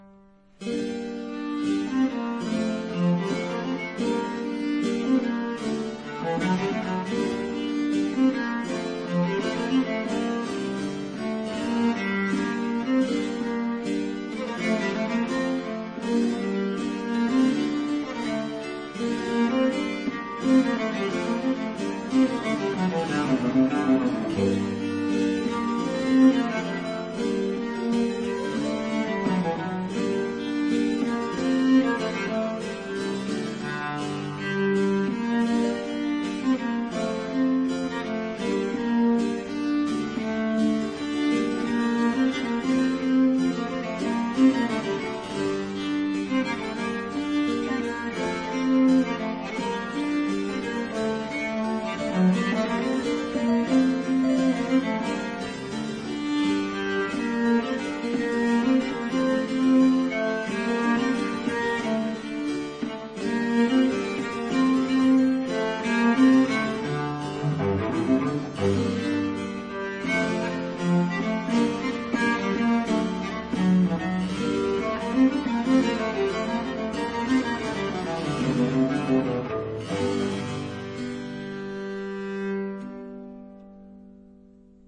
都偏向簡單的架構與簡單的旋律，與簡單的情感表達。
而表達的情感甚多是低沈與悲傷。
另外，這些錄音使用了十把大提琴，
只有一把是古琴，其他九把都是複製。